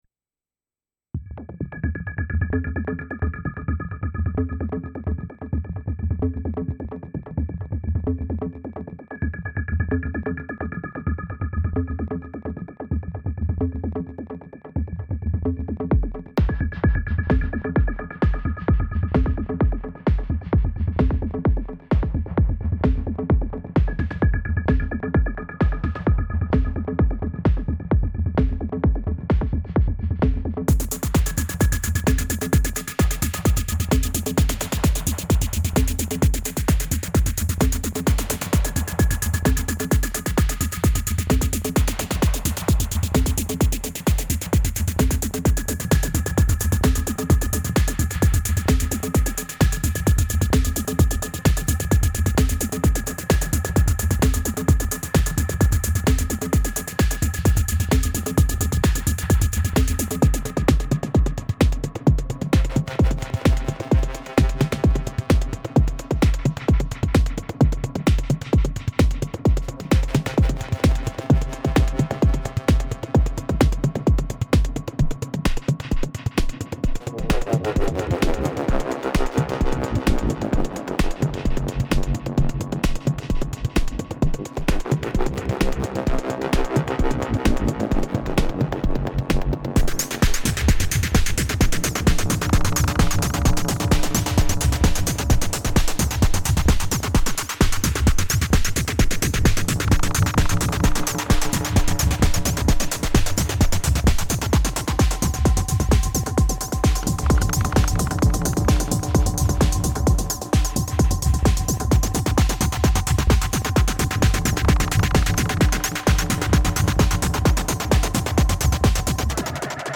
A techno journey into outer space.
Recorded straight from the Syntakt.